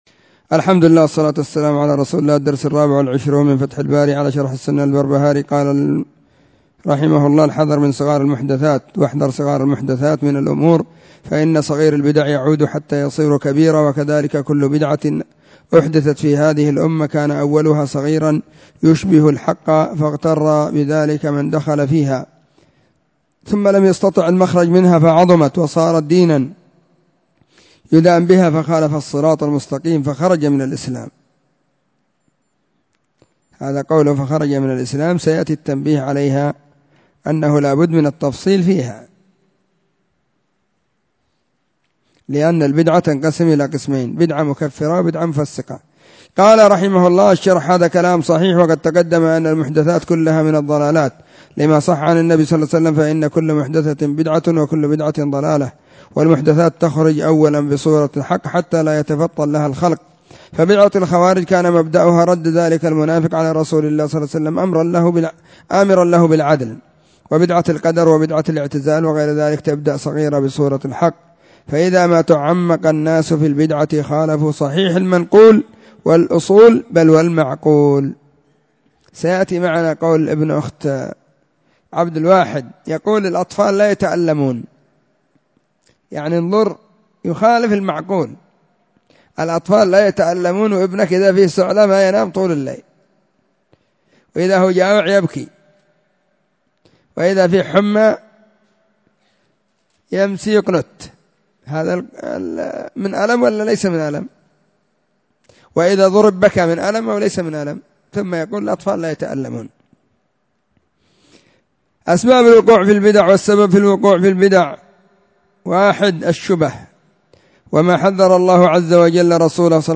الدرس 24 من كتاب فتح الباري على شرح السنة للبربهاري
📢 مسجد الصحابة – بالغيضة – المهرة، اليمن حرسها الله.